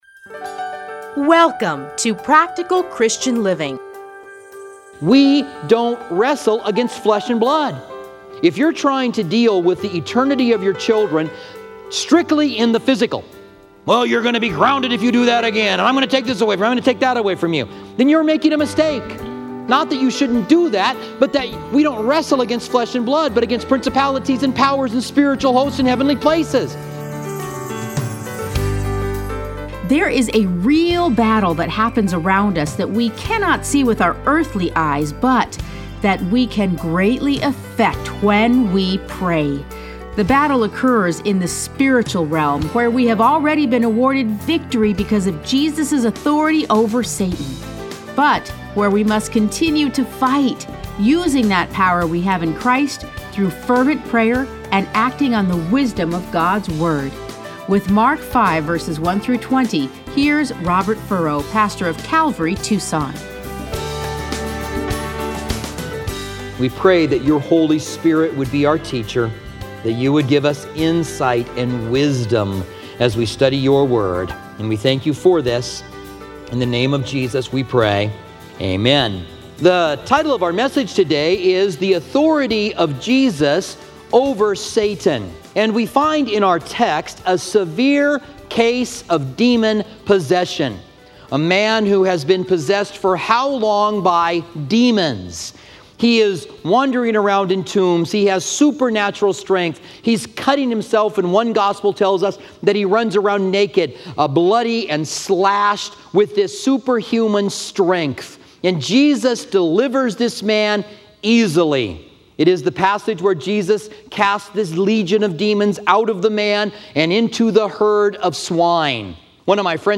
Listen to a teaching from Mark 5:1-20.